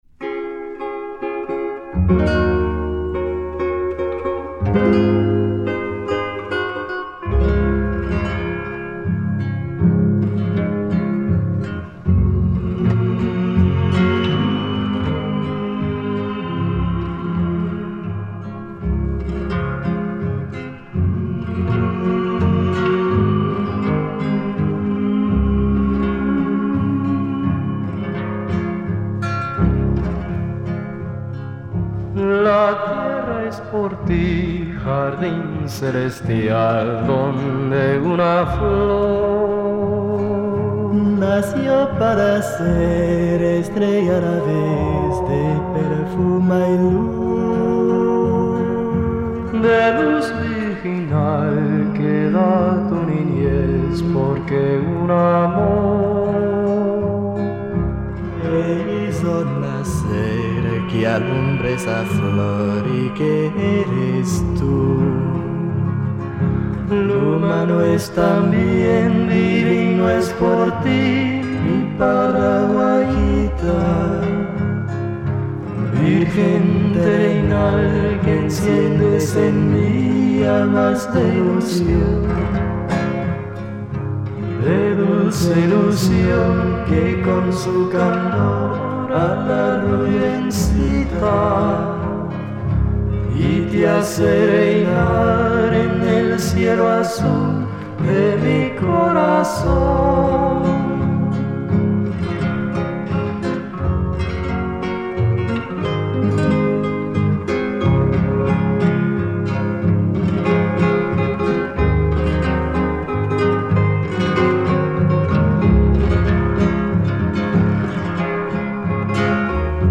a kind of Latin-American folk song.